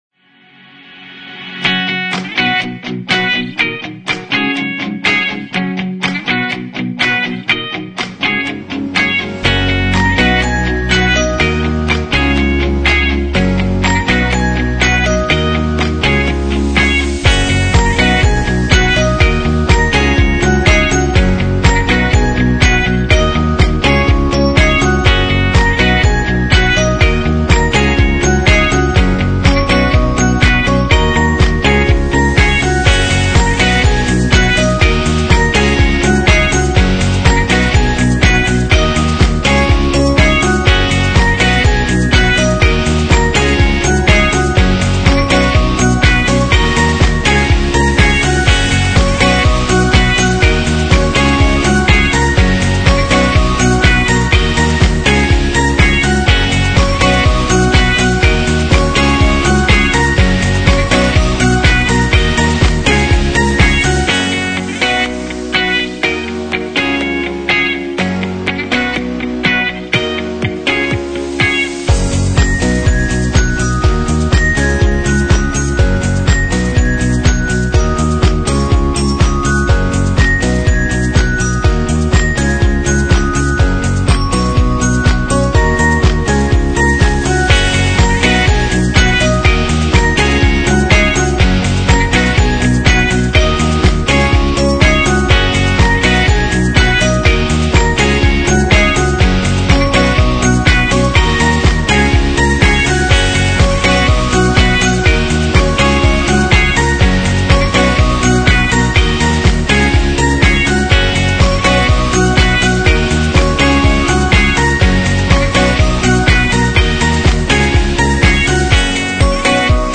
描述：这是一首鼓舞人心的乐观向上的励志歌曲，伴随着吉他、钢琴、钟声、钟琴和管弦乐的元素。